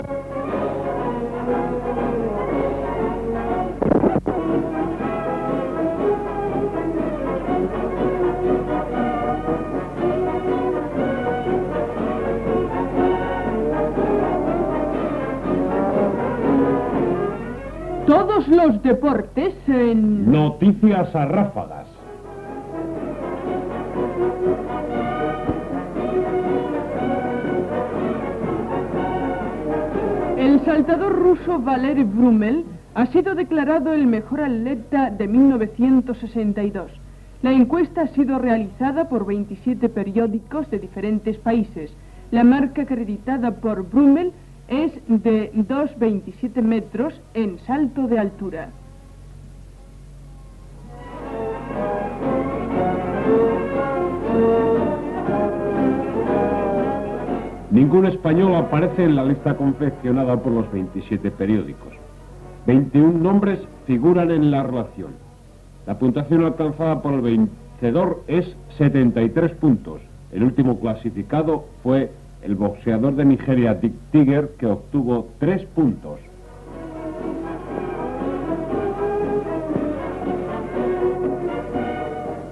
Careta del programa i millors esportistes de l'any 1962, segons els diaris
Esportiu
Extret del programa dedicat al cinquentenari de Radio Panadés emès l'any 1982.